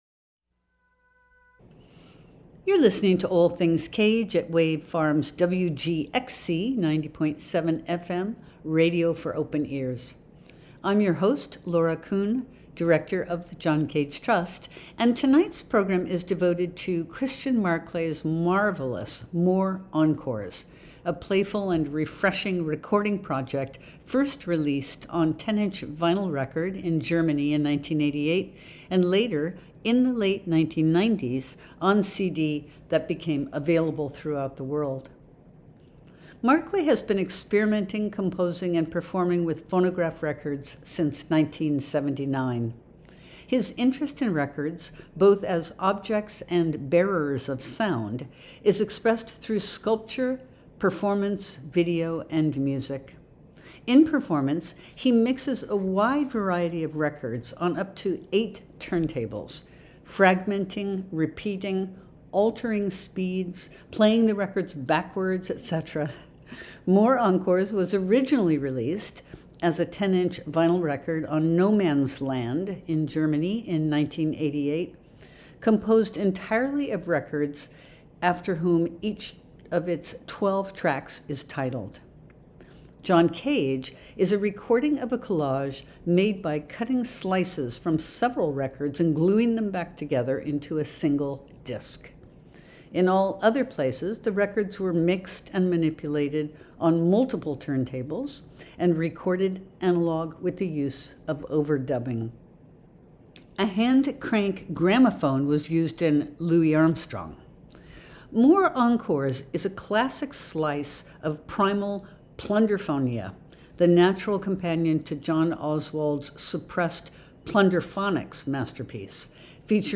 a series of viciously beautiful vinyl collages